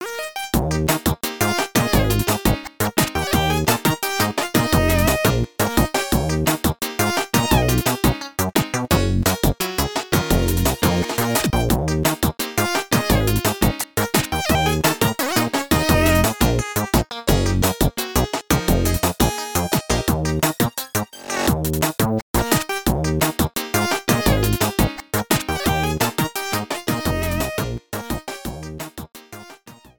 Cropped to 30 seconds, fade out added